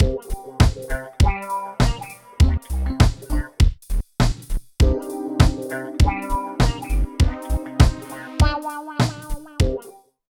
110 LOOP  -R.wav